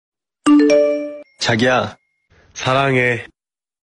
Korean Ringtones, Ringtones